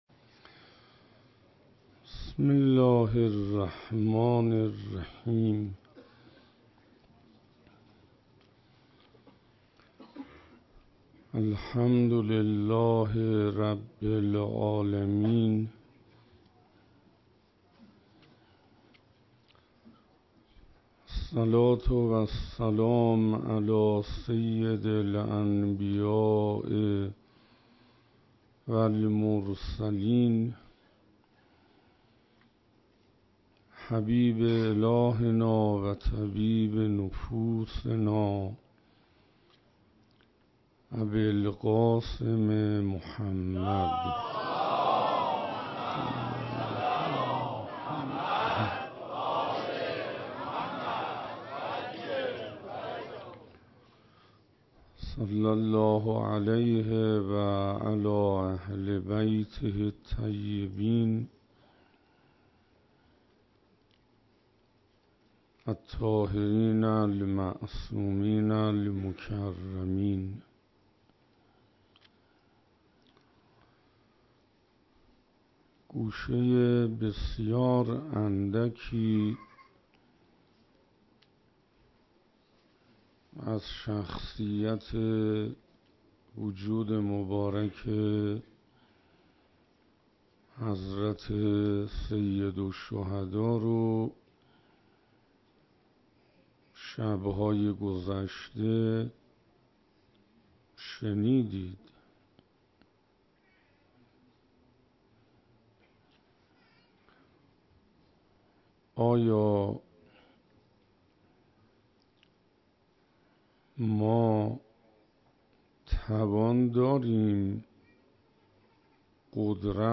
شب پنجم محرم 96 - حسینیه حضرت ابالفضل علیه السلام (تهرانپارس)